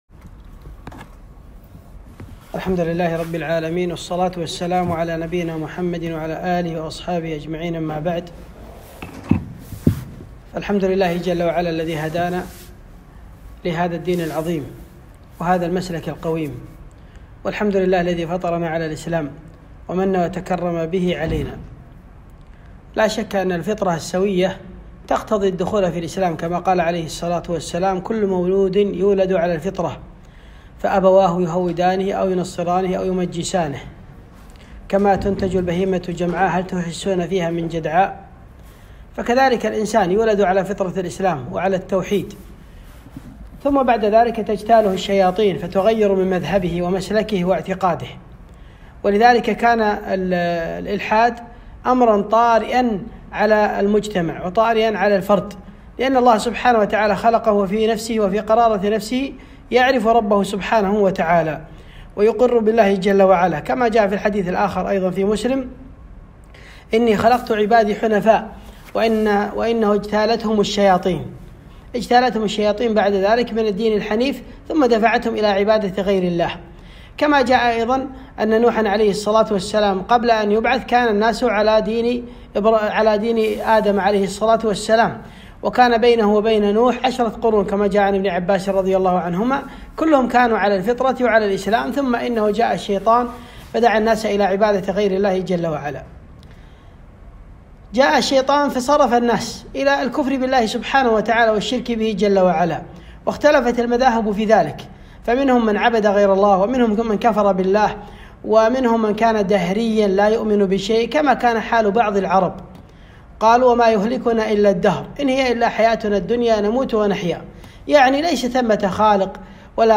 محاضرة - خطر الإلحاد على الناشئة